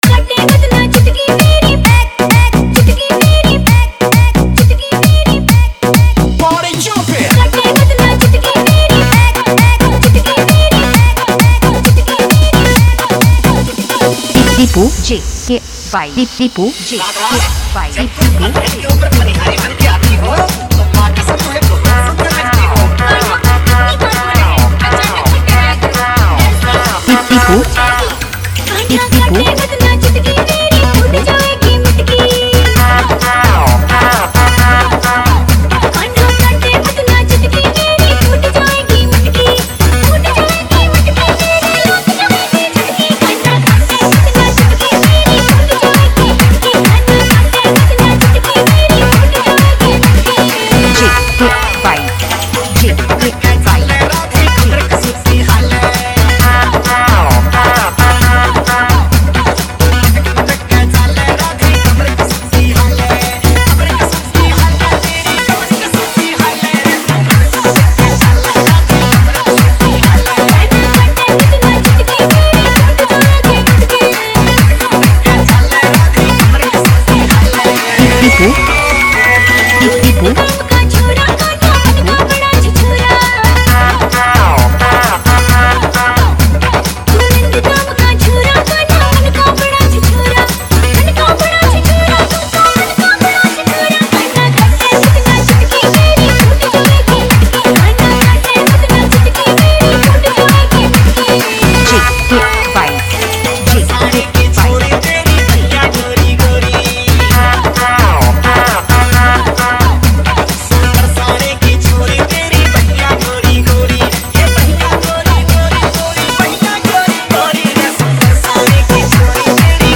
Krishna bhakti dj mix
Bhakti dance dj mix
Krishna bhajan dance remix